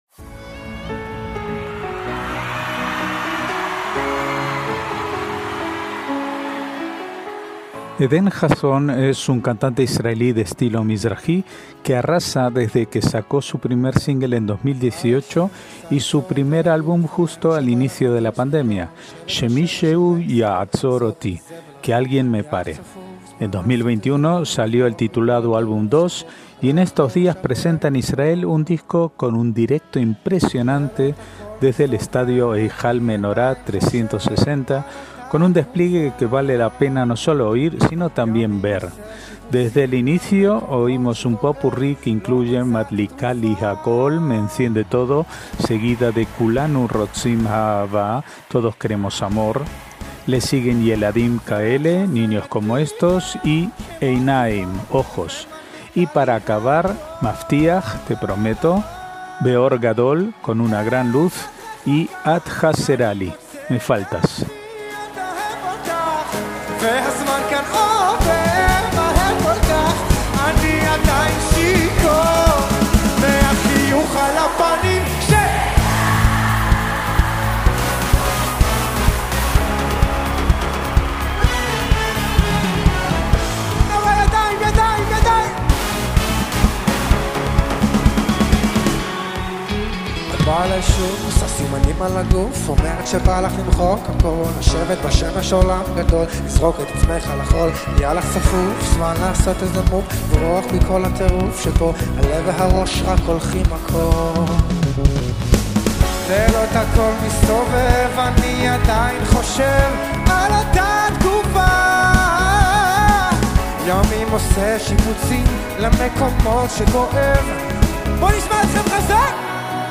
MÚSICA ISRAELÍ
Desde el inicio oímos un popurrí